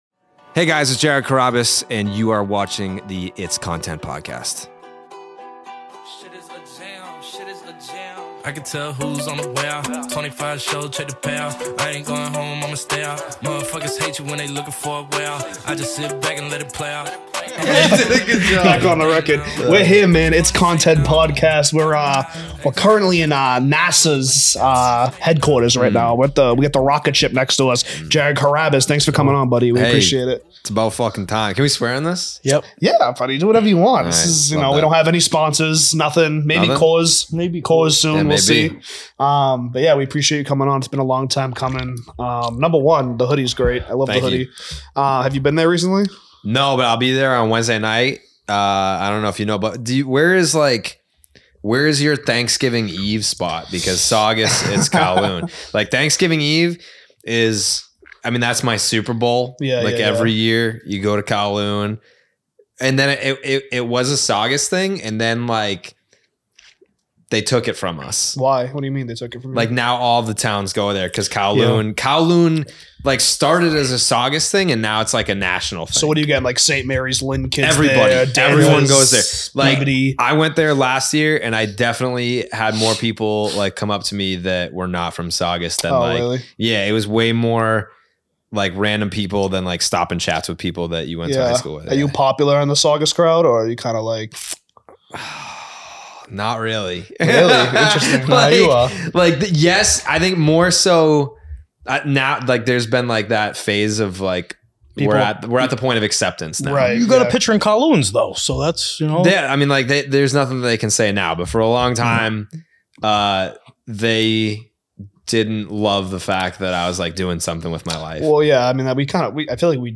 This was a fun episode with a lot of laughs and definitely not the last time Jared will be doing content with the boys.